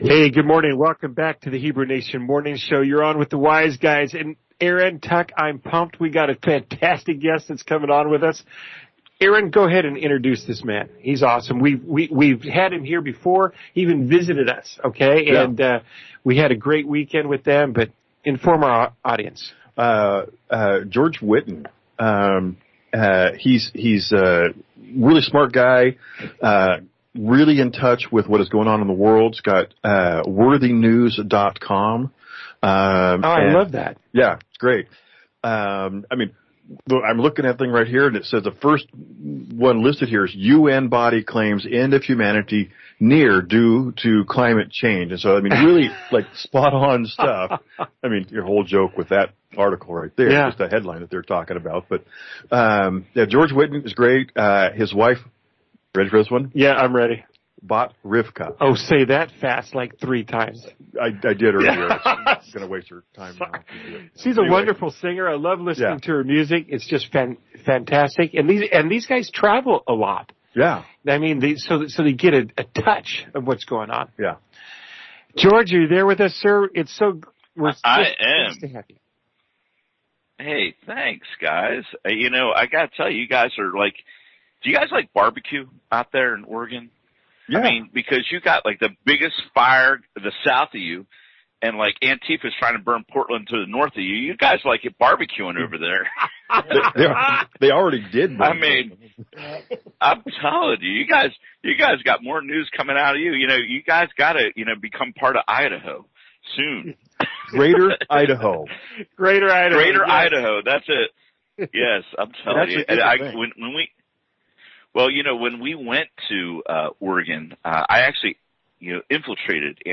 Hebrew-Nation-Radio-Interview.mp3